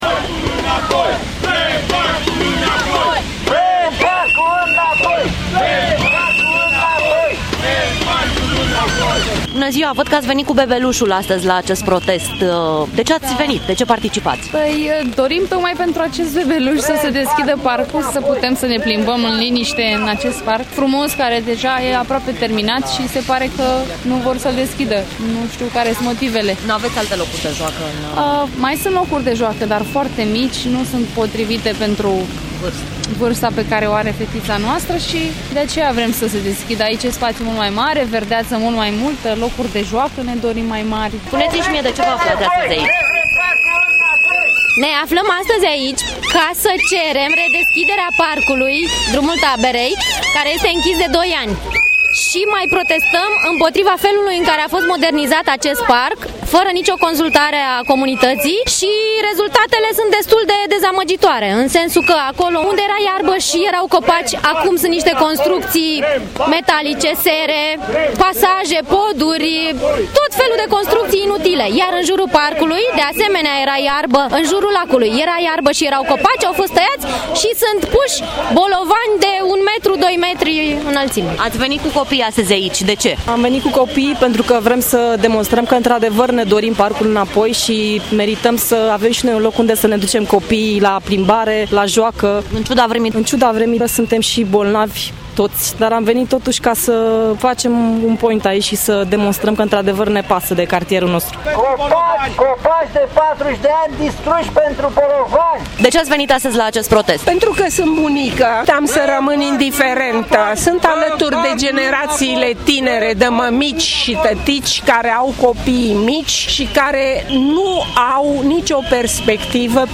Vrem sa se sesizeze institutiile statului”, reproseaza oamenii:
oameni-nemultumiti.mp3